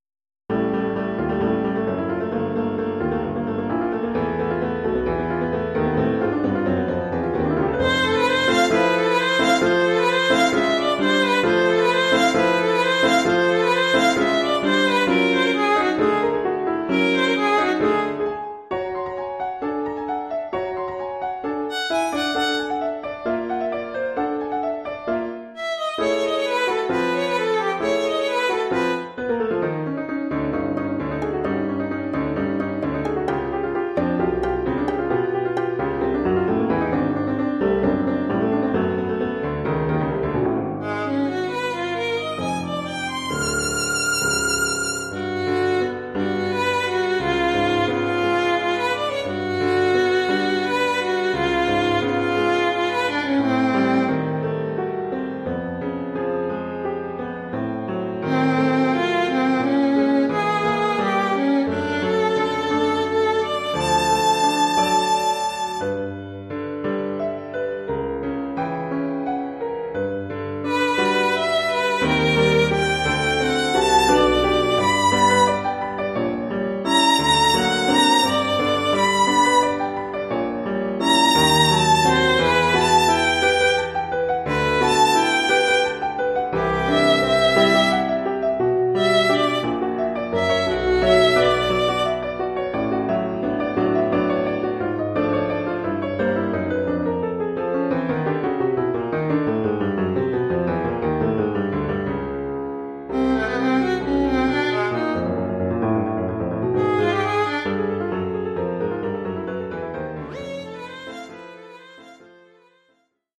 Oeuvre pour violon et piano.